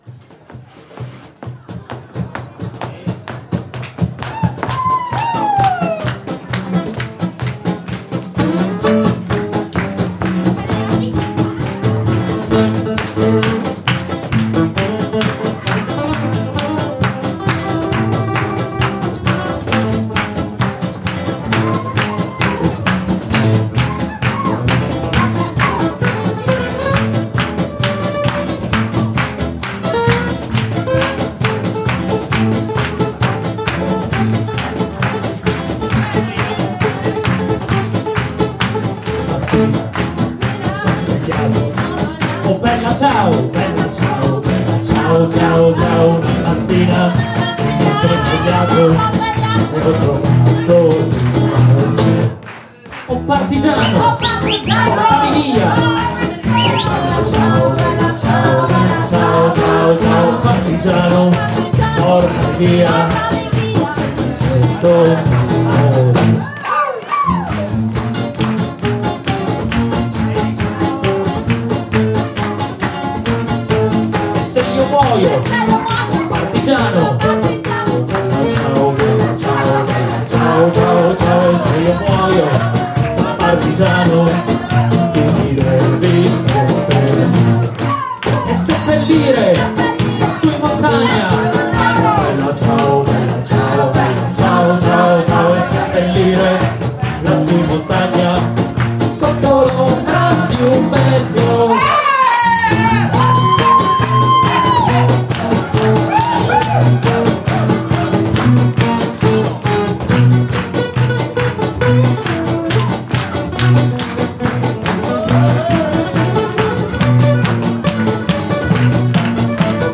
Chanté, dansé et à la demande du public, repris 2 fois à la fin du concert des Camerieri italiani!
Une vraie découverte pour le public qui s’est laissé entrainer dans l’univers acoustique des 5 musiciens-eux même emportés par la foule..
En variant les genres et en laissant place à l’improvisation, le spectacle était à la fois divertissant et prenant. Les percussions ( congas, cymbales, cajon, guiro,..)
la basse acoustique